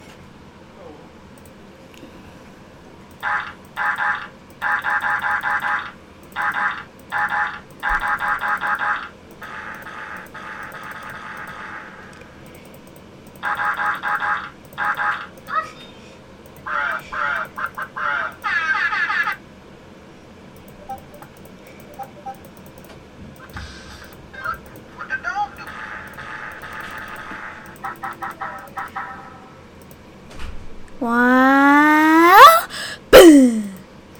Category 🎵 Music
fart gas random sound effect free sound royalty free Music